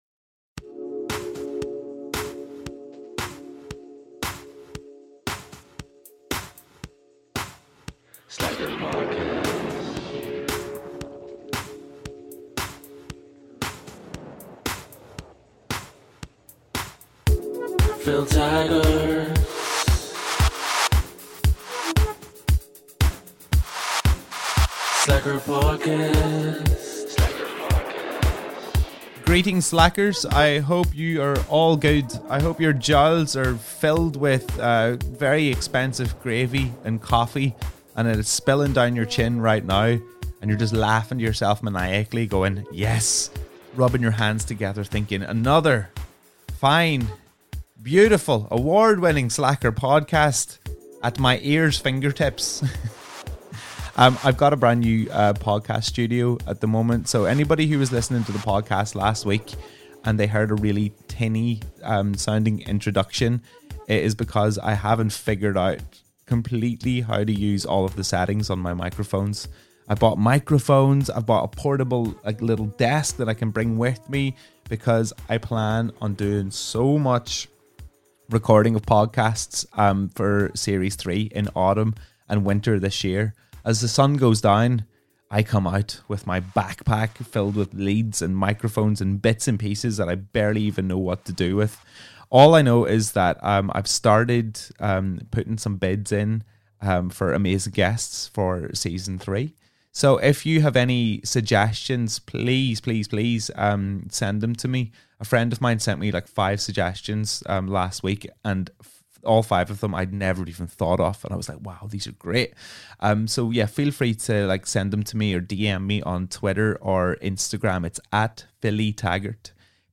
She was the first guest to do a podcast in my kitchen.